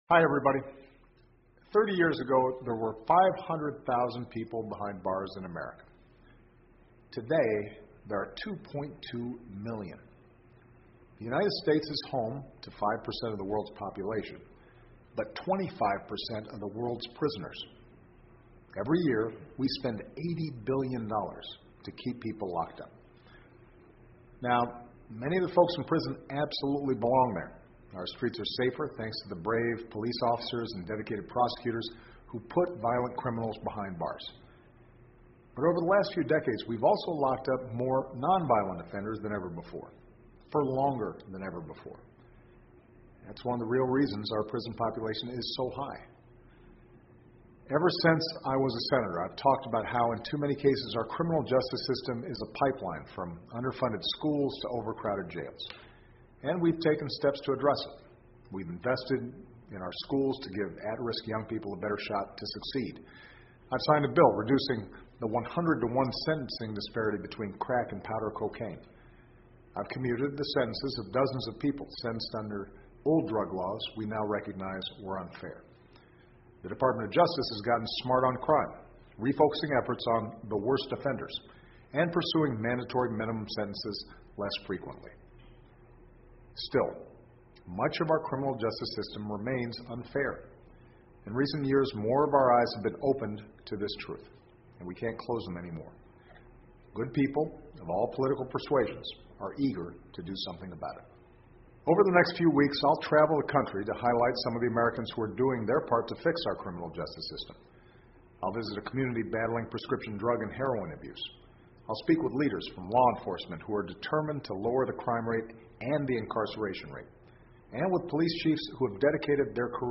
奥巴马每周电视讲话：总统呼吁进行司法改革 听力文件下载—在线英语听力室